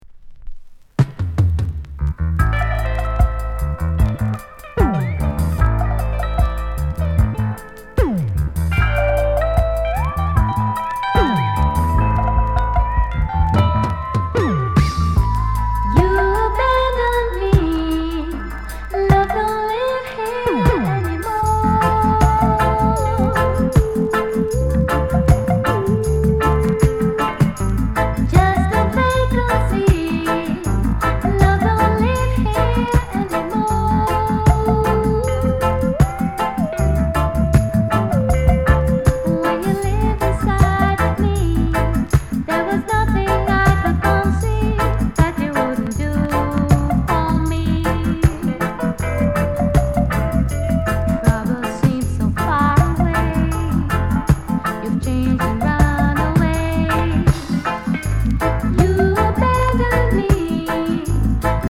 Sound Condition VG(OK)
LOVERS ROCK